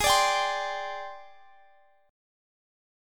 Listen to G#M7b5 strummed